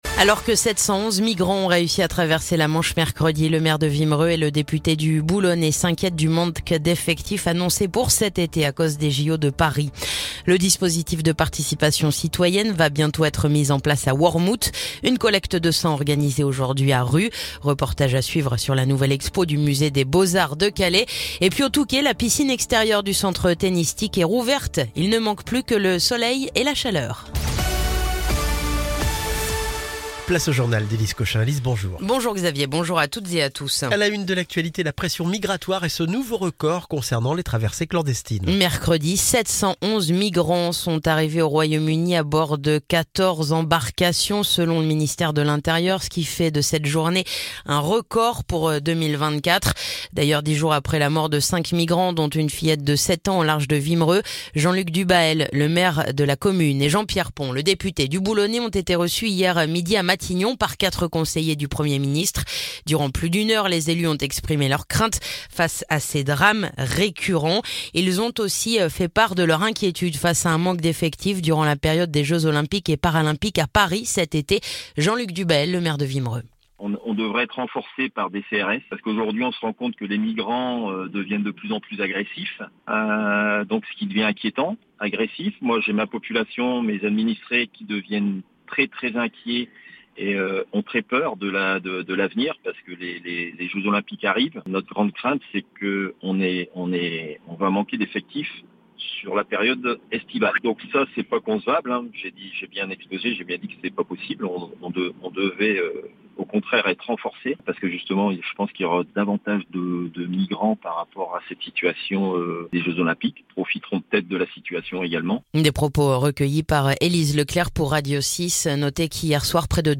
Le journal du vendredi 3 mai